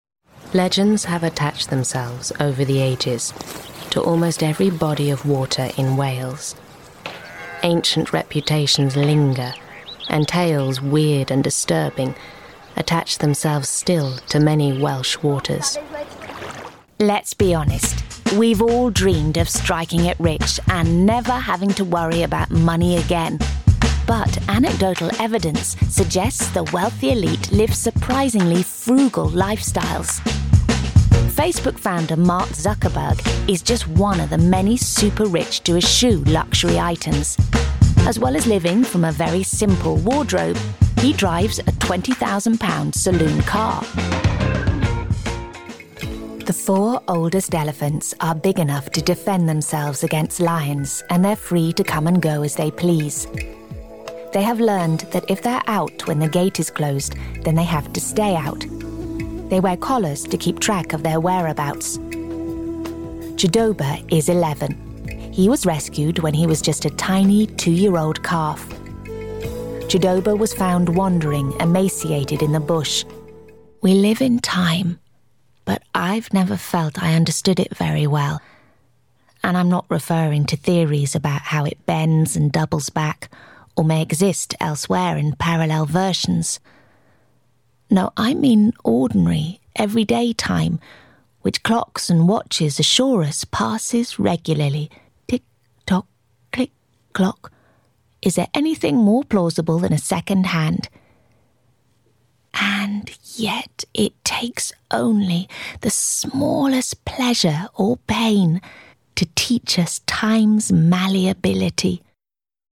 Female
English (British)
Adult (30-50), Older Sound (50+)
Serious, Playful And Soulful
All our voice actors have professional broadcast quality recording studios.